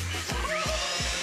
wilhelm guffaw.wav